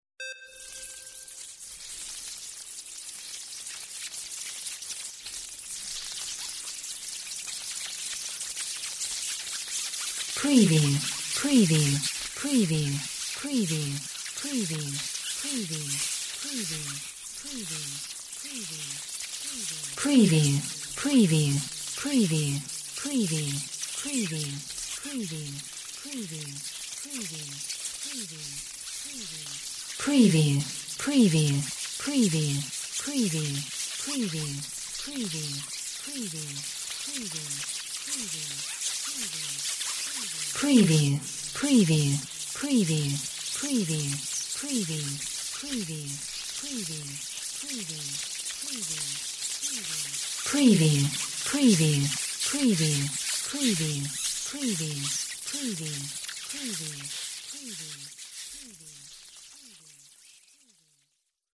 Bats squeaking
Stereo sound effect - Wav.16 bit/44.1 KHz and Mp3 128 Kbps
PREVIEW_ANM_BATS_SQUEAKS_WBHD01.mp3